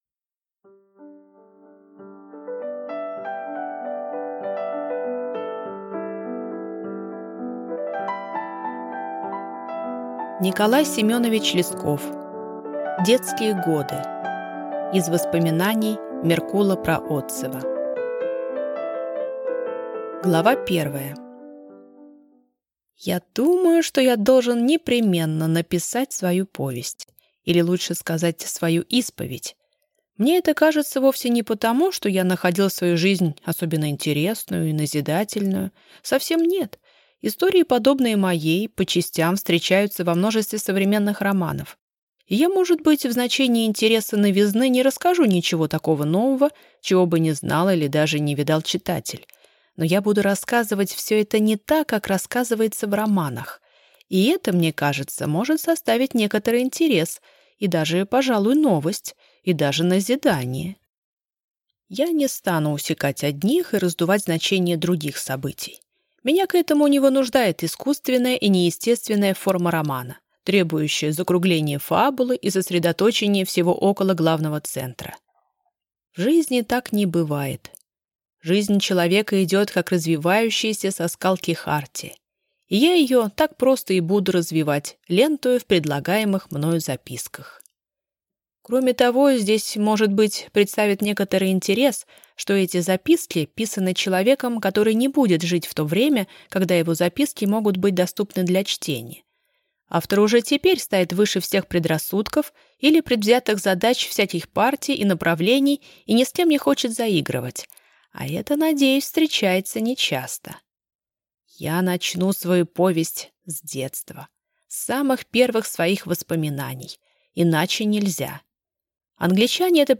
Аудиокнига Детские годы | Библиотека аудиокниг